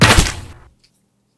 Crossbow_LaunchArrow 02.wav